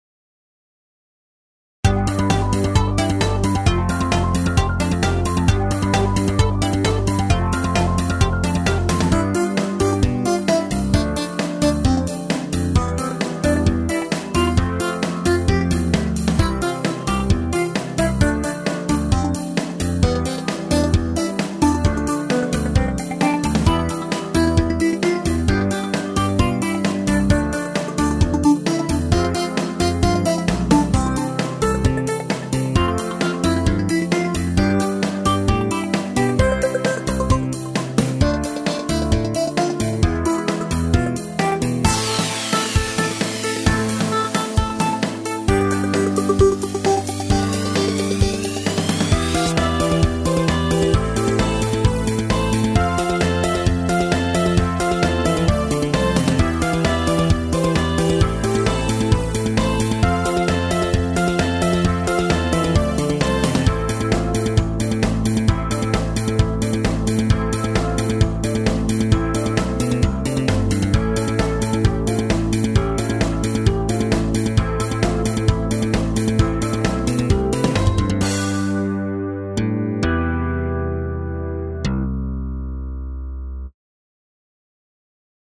エレクトロニカショートポップ明るい
BGM